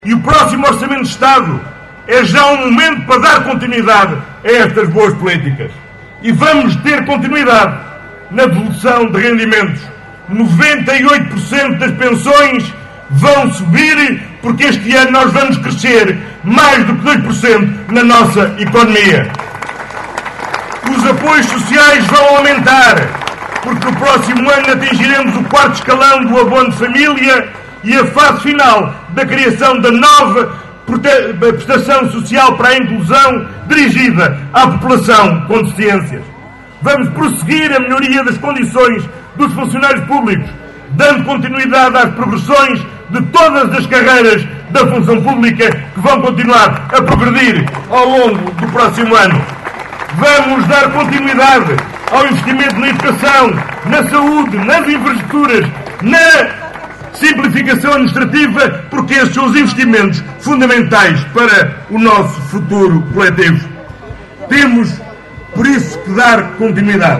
Os socialistas rumaram a norte no passado sábado (25 de agosto) para a habitual “rentrée” política que este ano teve lugar no Parque Municipal em Caminha.